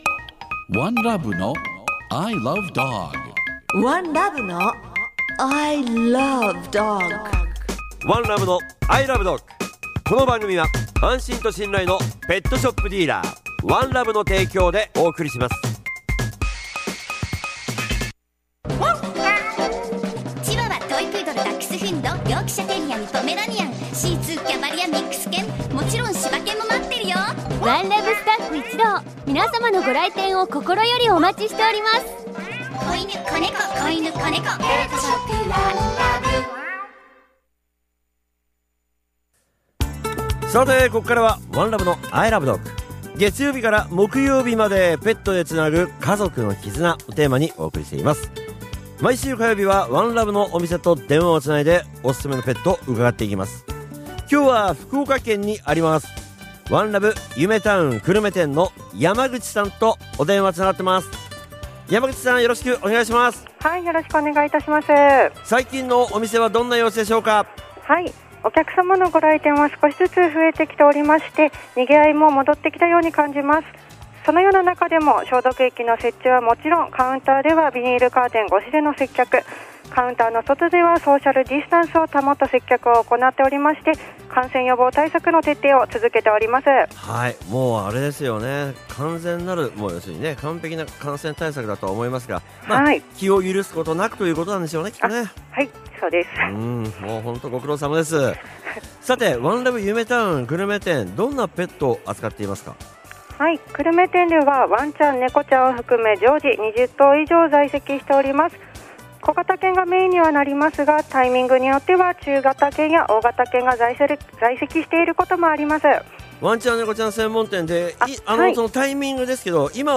I LOVE DOG ラジオ番組
月曜は、街角突撃インタビューが聞けるワン！